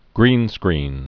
(grēnskrēn)